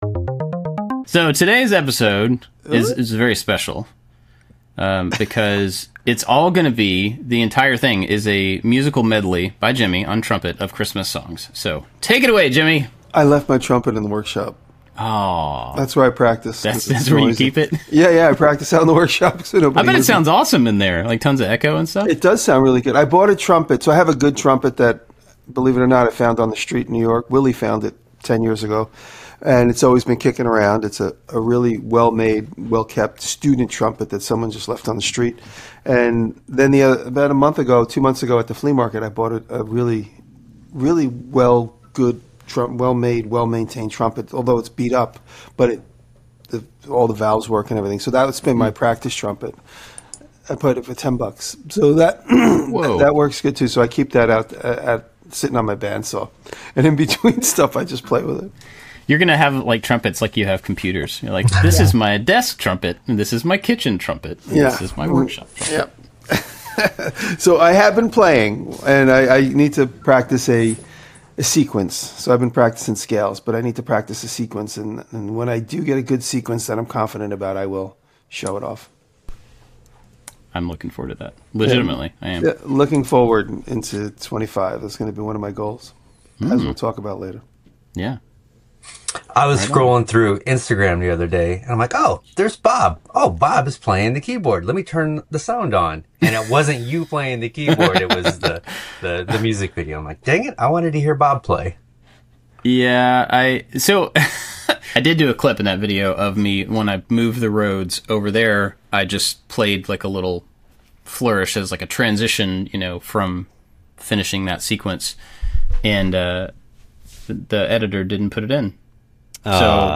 Three different makers with different backgrounds talking about creativity, design and making things with your bare hands.